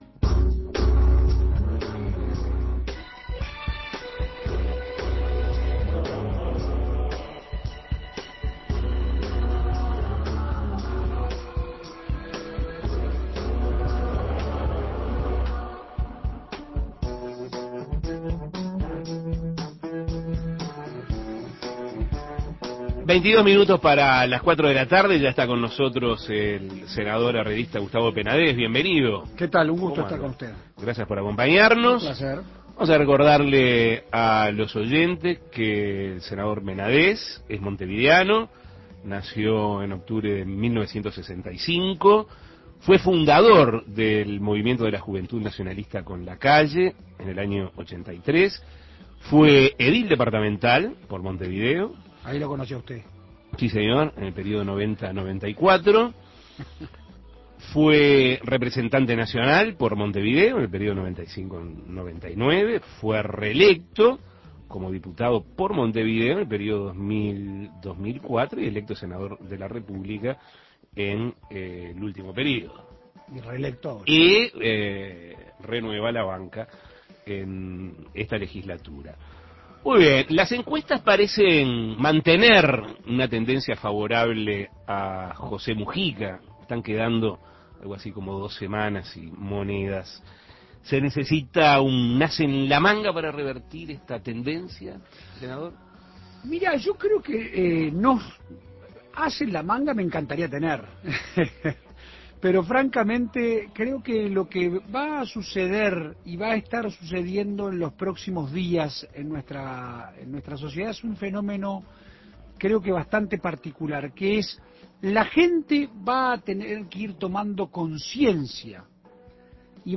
El senador herrerista Gustavo Penadés dialogó sobre las elecciones del próximo 29 de noviembre, las encuestas de cara a estos comicios, la relación que habrá entre oficialismo y oposición (si el Frente Amplio es gobierno), y cómo será la interna nacionalista si Lacalle pierde la elección. Escuche la entrevista.